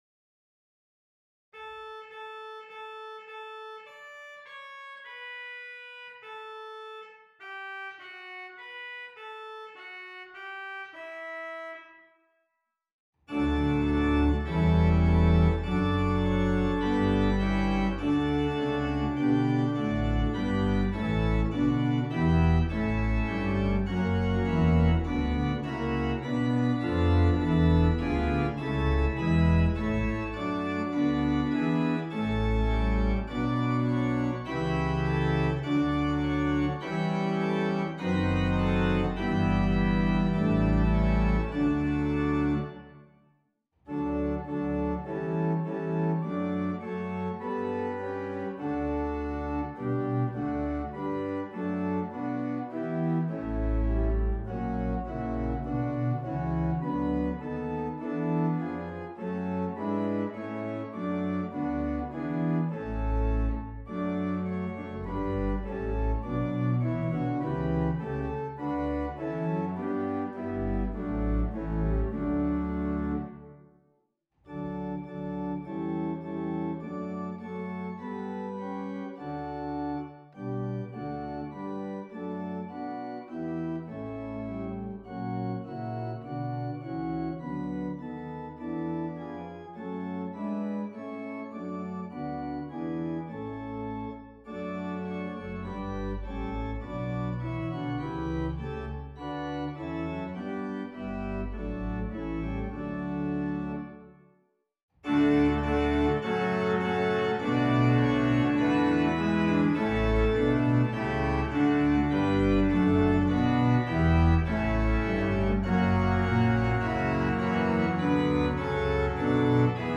Organ: Moseley
praise-my-soul-stannesmoseley.wav